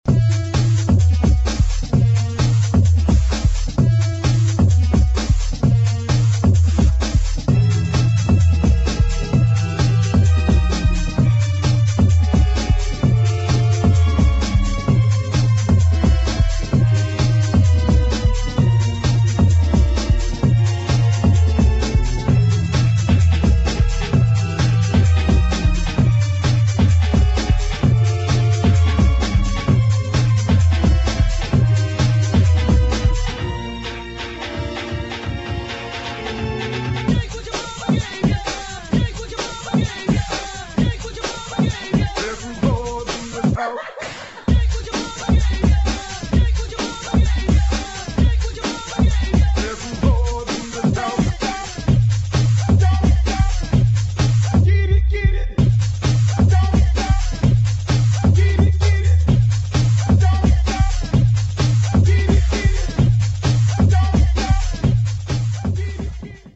[ DUBSTEP / UK GARAGE / BREAKS ]